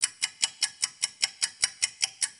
timer.wav